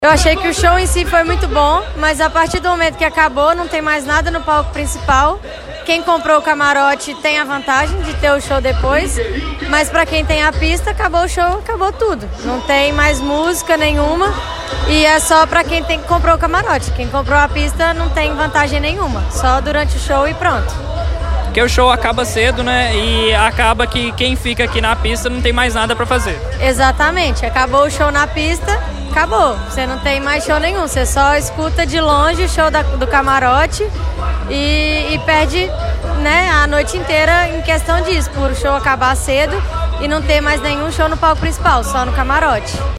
Para uma das ouvintes o show foi bom, mas o pouco tempo de duração e não ter outras atrações deixaram a desejar: